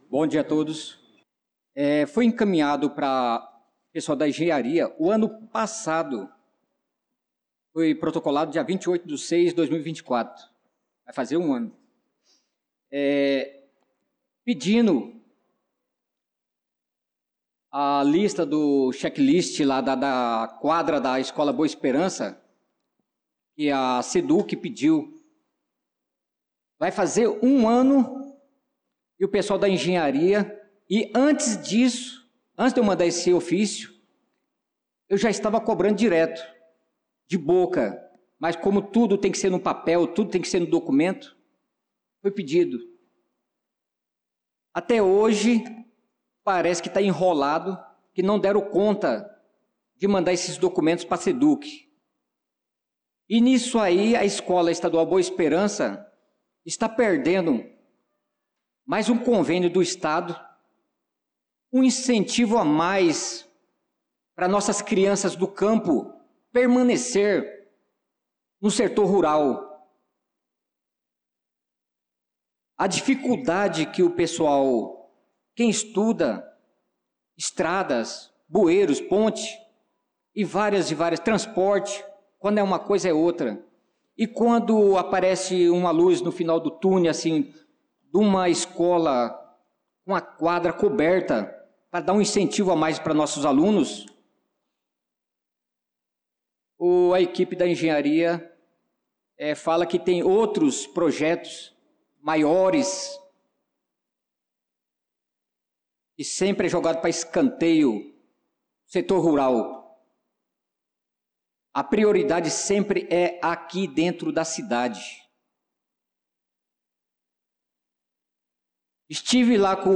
Pronunciamento do vereador Naldo da Pista na Sessão Ordinária do dia 28/04/2025